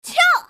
slayer_f_voc_attack02_c.mp3